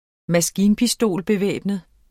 Udtale [ -beˌvεˀbnəð ]